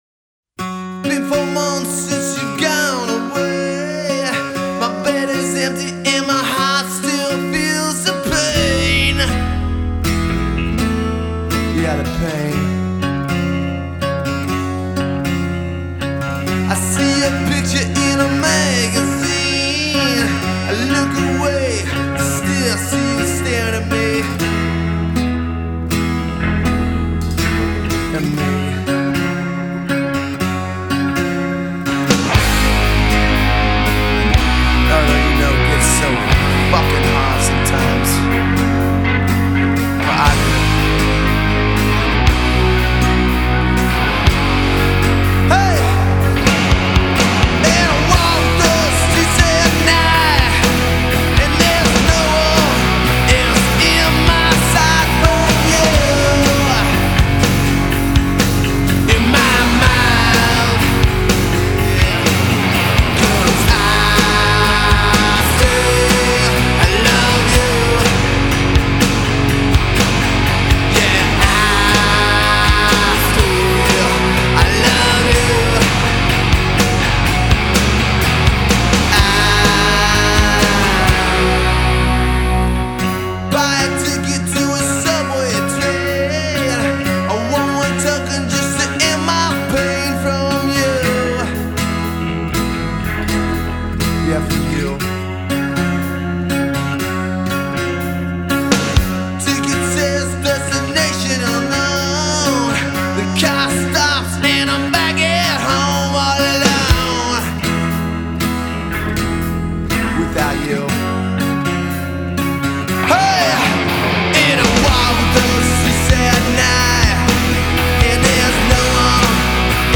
Thrash Metal